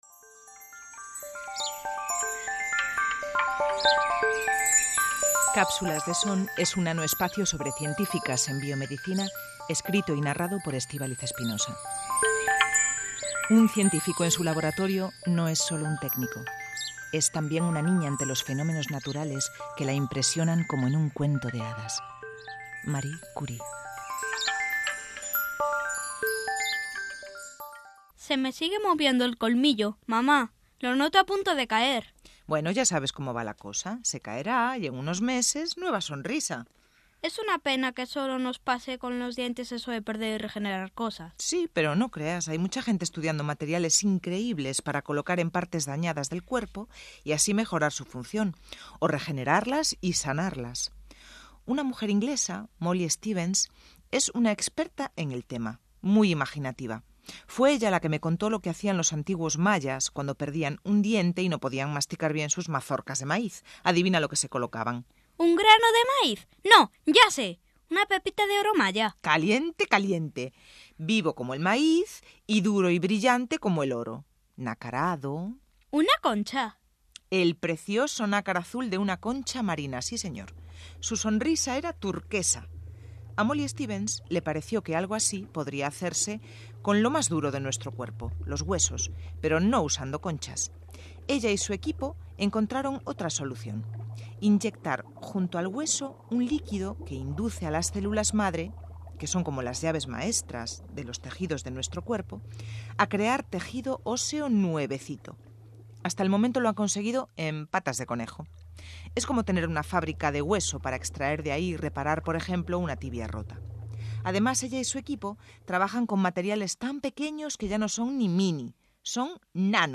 Miniserie de radio
Grabación en los estudios de RadioVoz